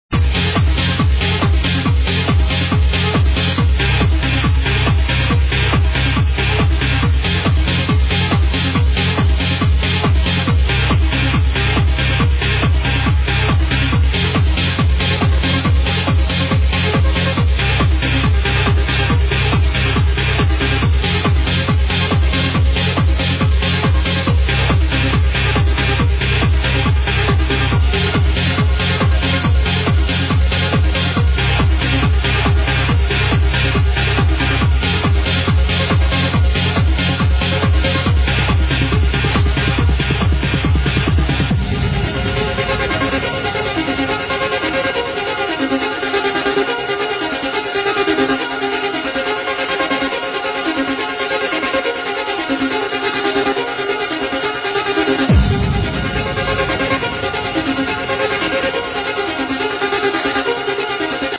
1996 House/Trance
it's from an unlabeled mix tape...